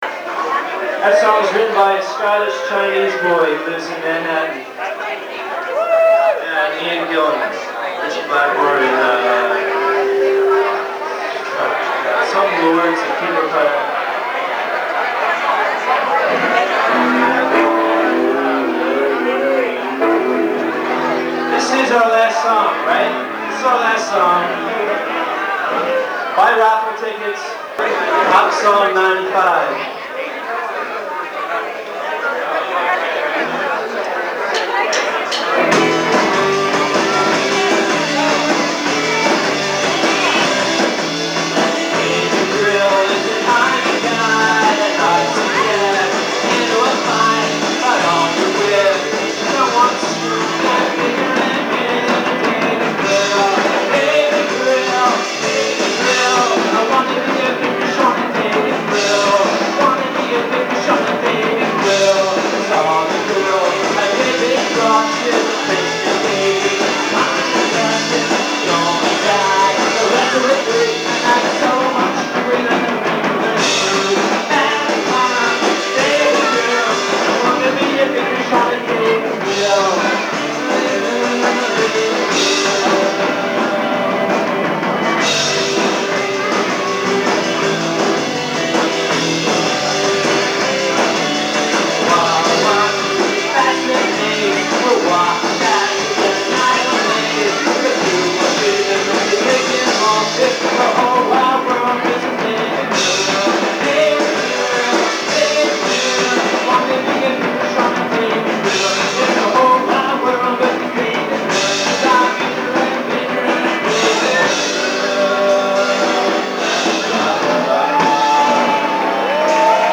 guitar
Bass
Drums
Live